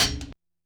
percussion02.wav